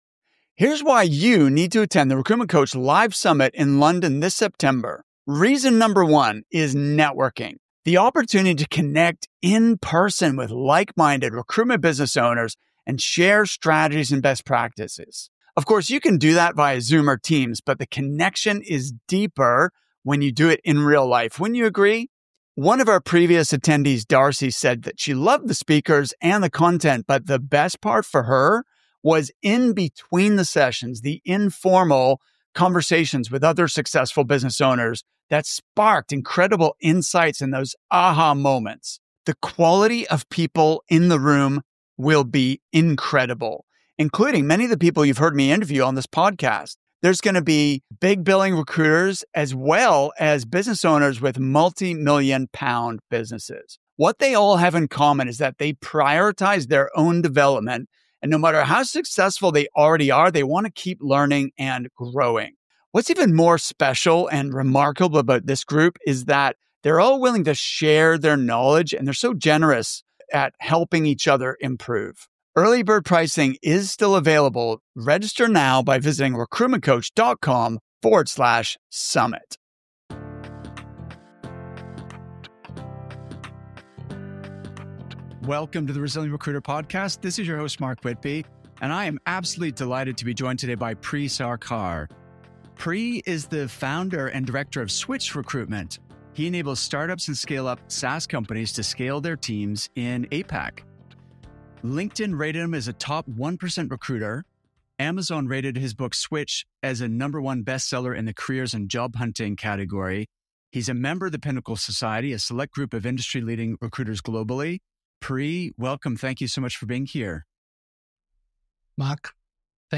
In this fascinating interview